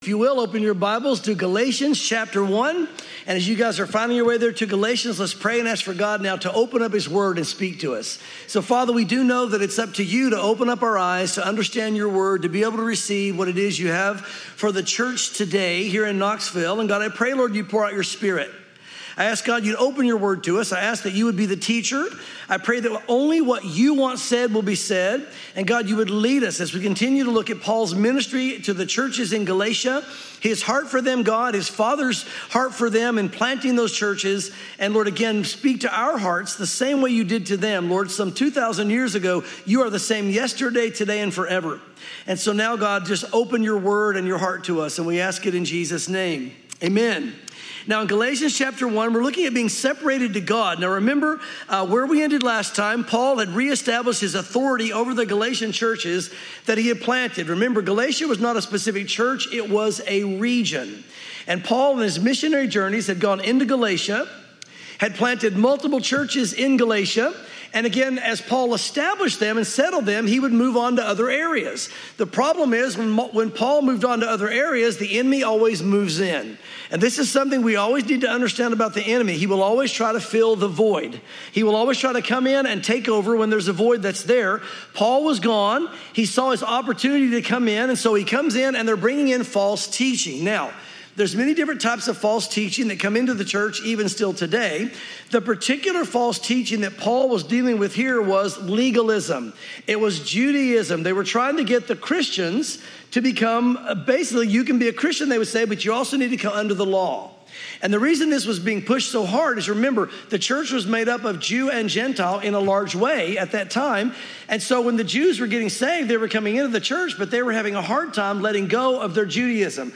Welcome to Calvary Chapel Knoxville!